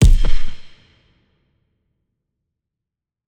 TM88 FunkKick1.wav